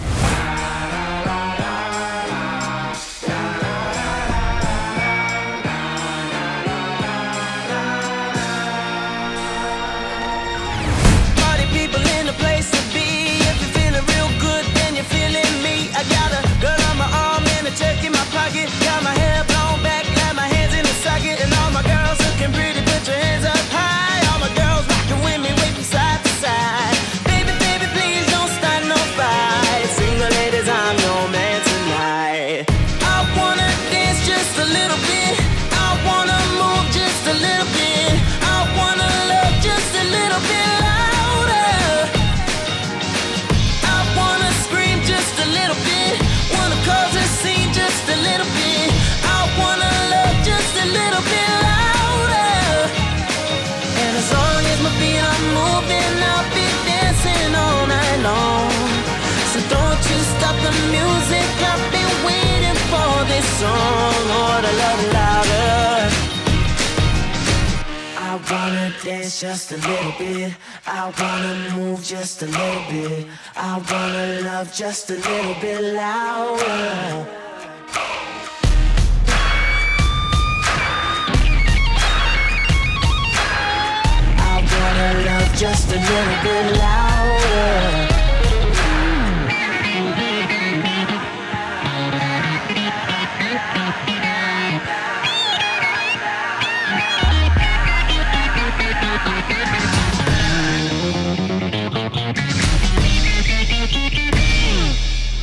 BPM177
Audio QualityCut From Video
POP ROCK